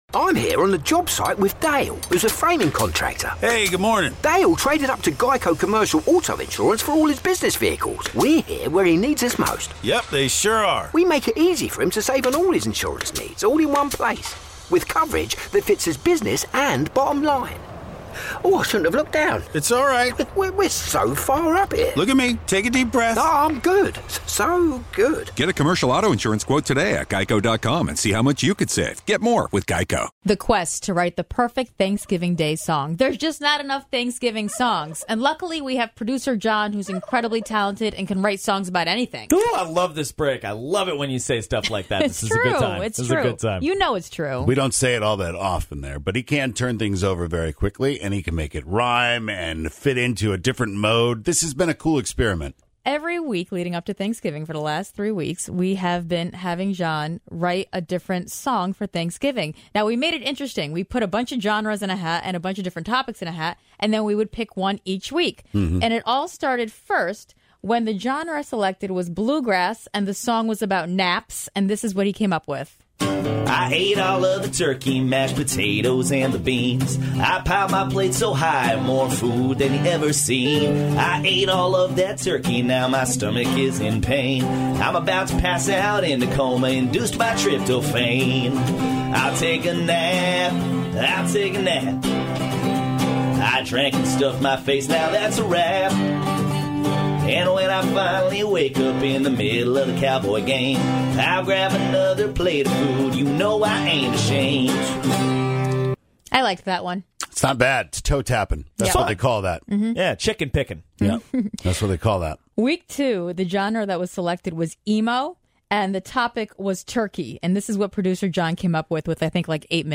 Is it the blue grass song? The emo song? The heavy metal song? Or the country song?